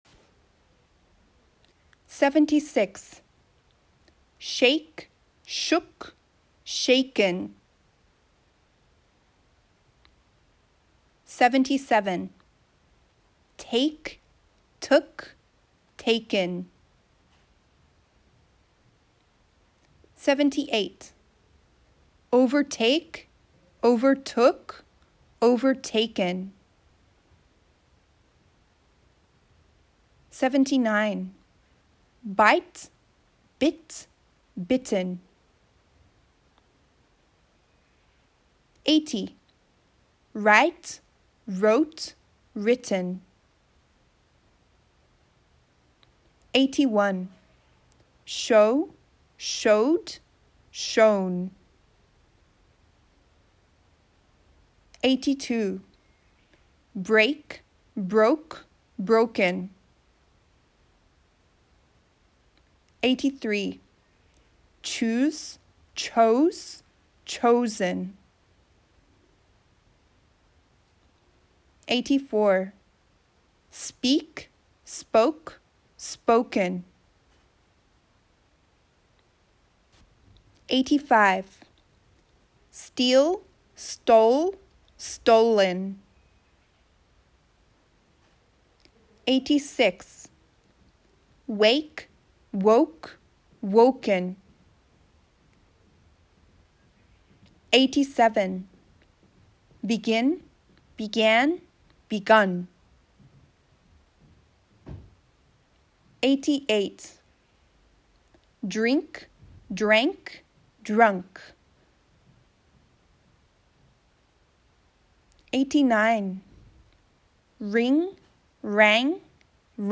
覚えるコツは、何度も聞いて、後に続けて練習することです。